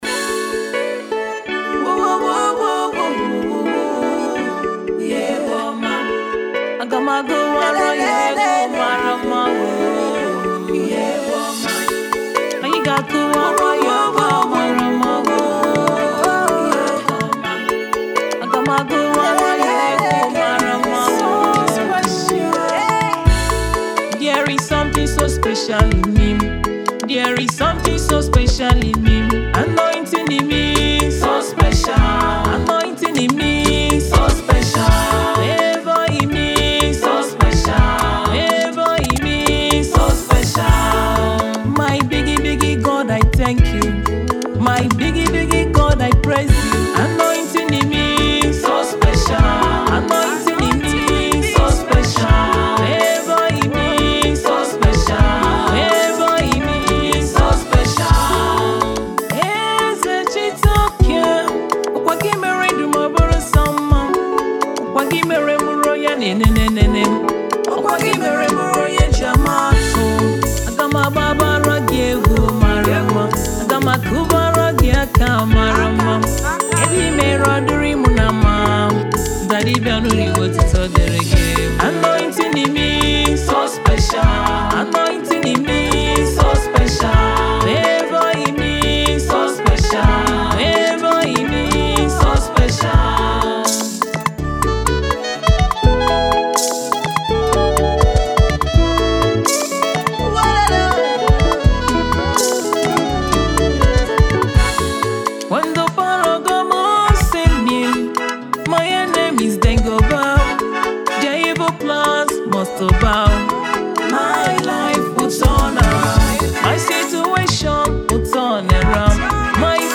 soul-stirring masterpiece
With her powerful vocals and heartfelt lyrics